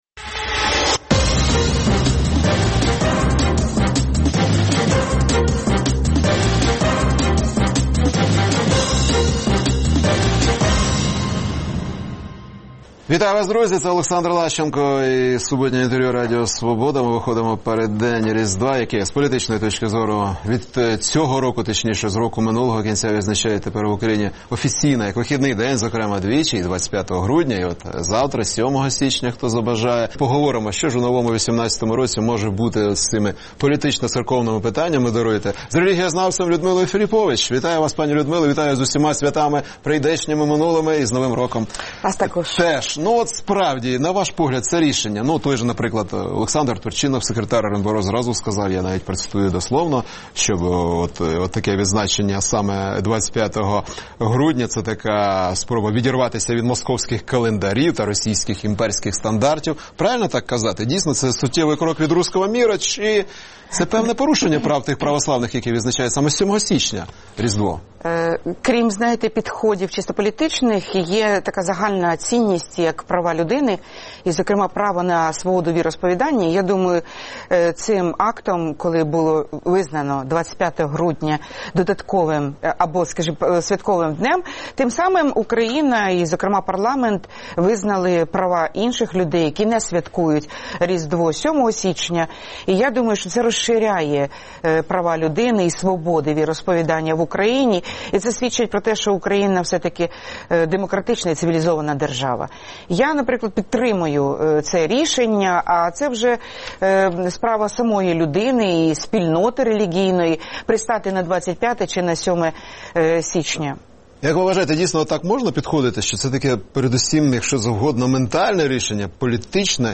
Суботнє інтвер’ю - розмова про актуальні проблеми тижня.